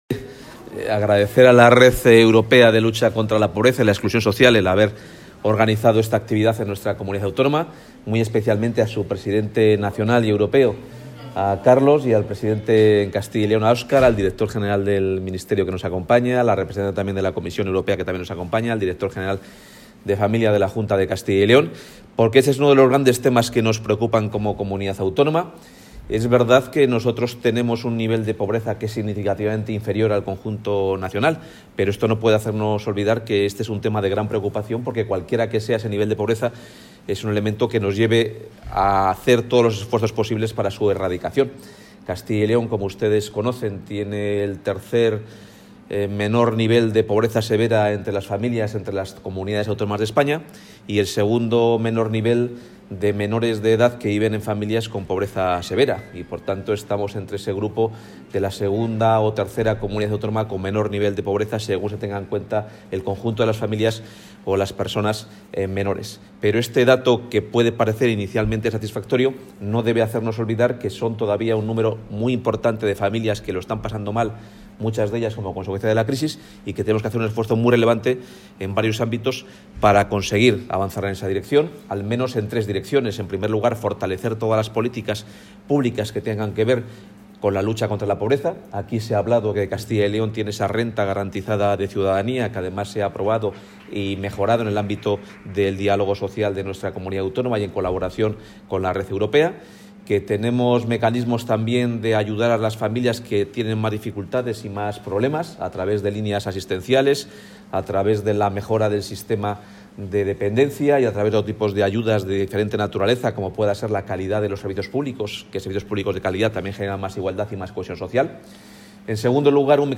Consejero de Empleo.